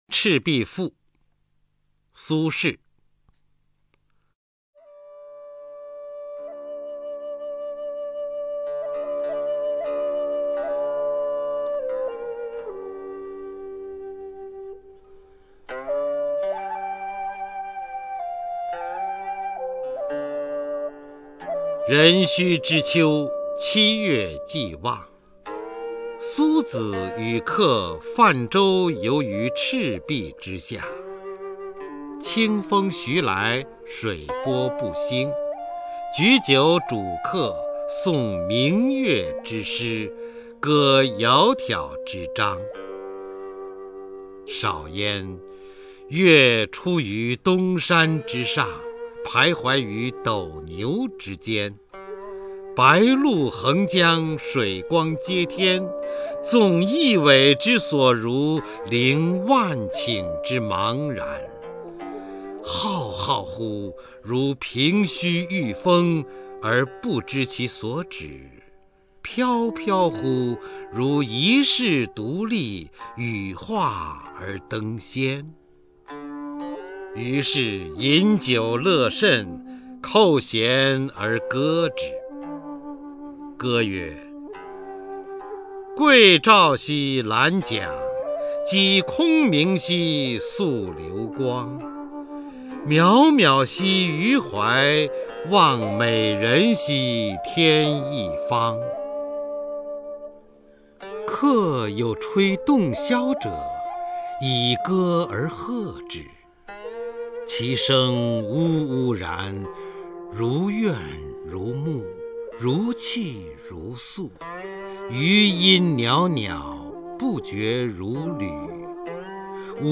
[28/11/2009]朗诵大师方明配乐朗诵苏轼名篇《赤壁赋》
朗诵  方明